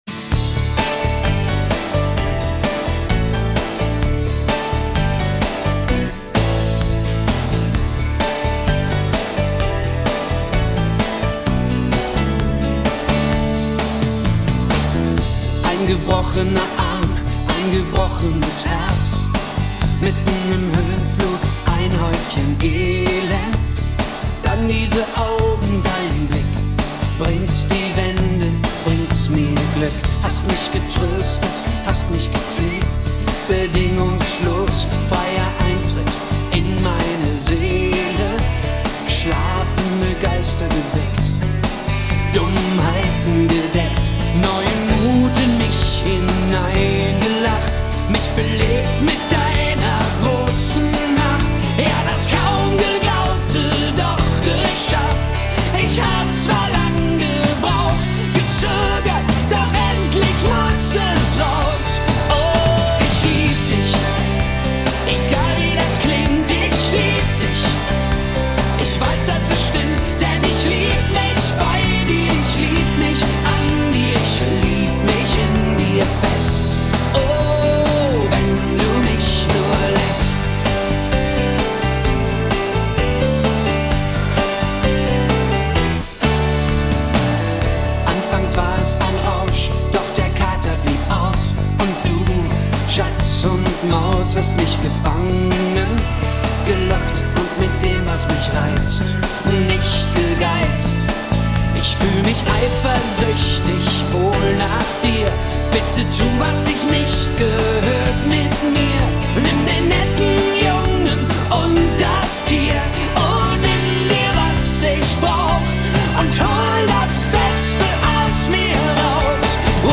Schlager alt